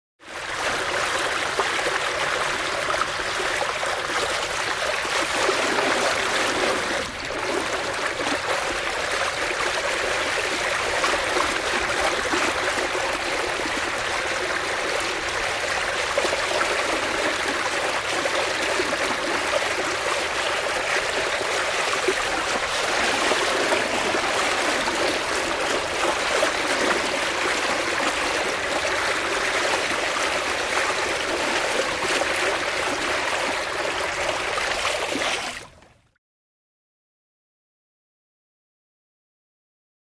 Water Laps 3; Lake Water Laps Against Sailboat Cutting Through Water.